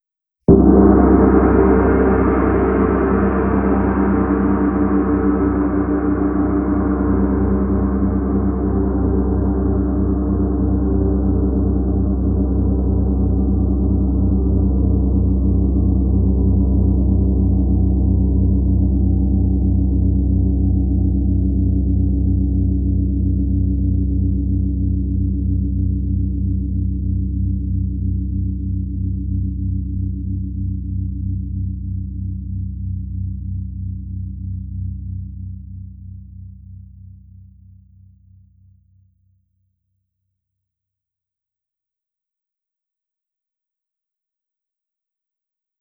“Behemoth” bronze gong, Black House, Witchcraft District (roll)
BH44Behemothstrike.wav